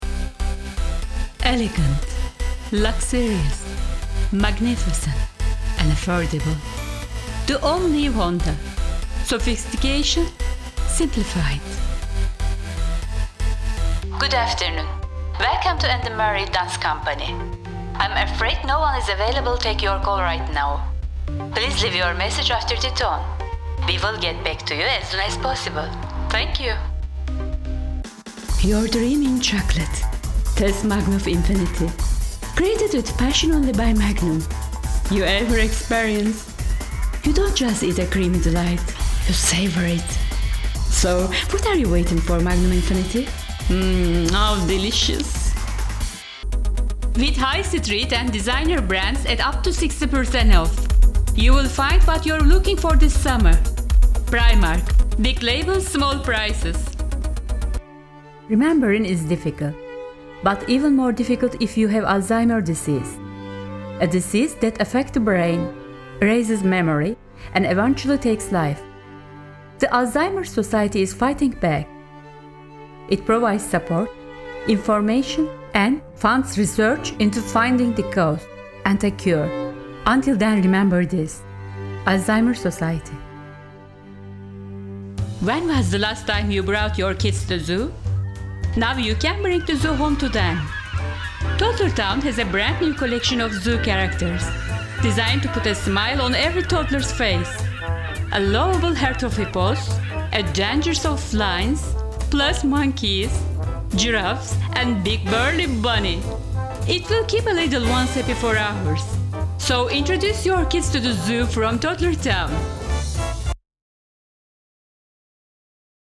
Sprechprobe: Industrie (Muttersprache):
Smooth, Natural, Sexy, Soft / Gentle, Sophisticated, Warm, YoungAngry, Announcer, Artistic, Charismatic, Cold, Concerned, Deep, Energetic, Friendly, Fun, Glamorous, Happy, Luxurious